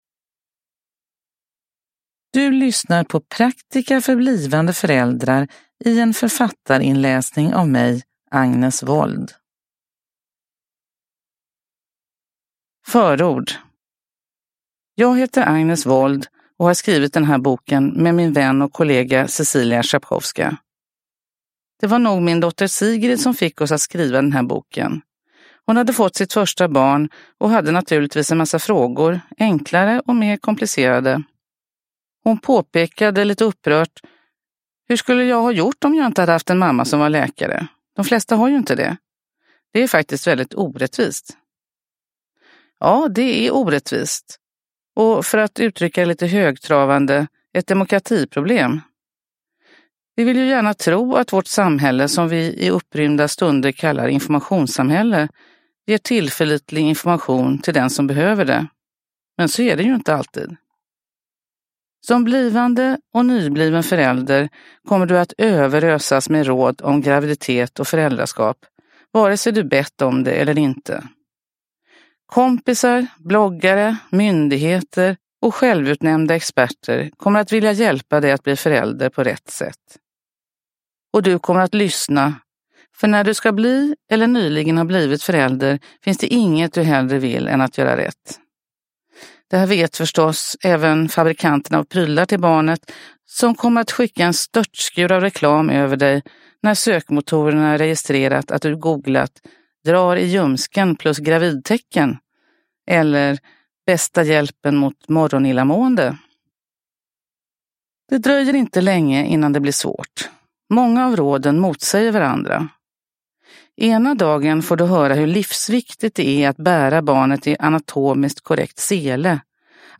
Uppläsare: Agnes Wold
Ljudbok